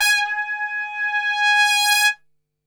G#3 TRPSWL.wav